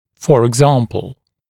[ˌfɔːrɪg’zɑːmpl] [eg-][фо:риг’за:мпл] [эг-]например